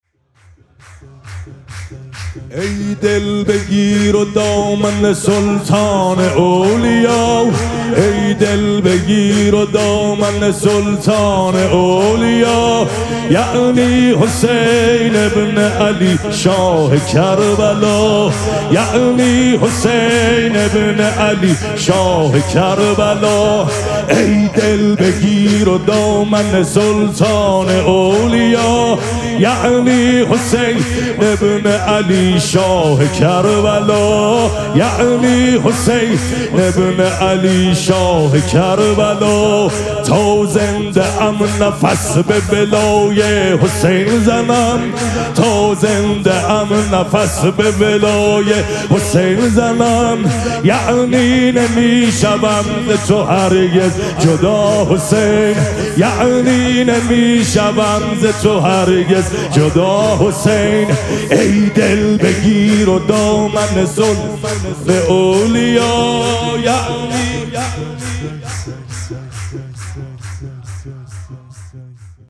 شب اول مراسم جشن ولادت سرداران کربلا
حسینیه ریحانه الحسین سلام الله علیها
سرود